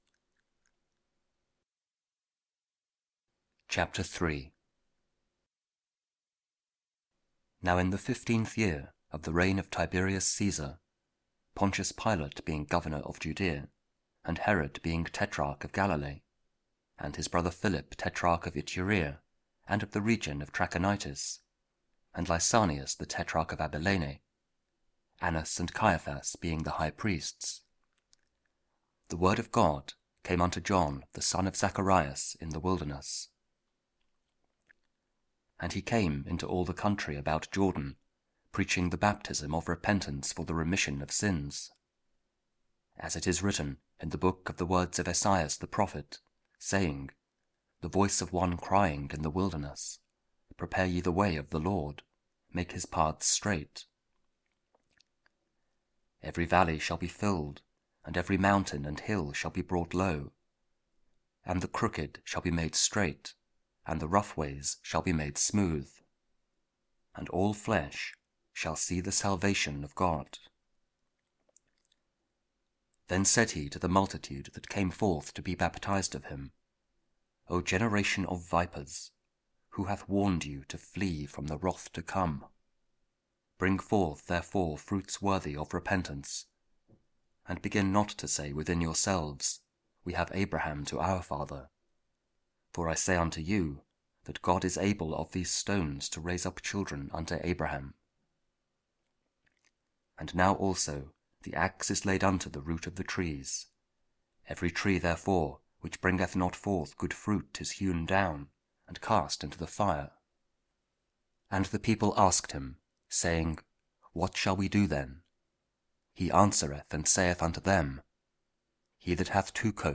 Luke 3: 15-16, 21-22 – The Baptism of the Lord (Year C) (Audio Bible, Spoken Word)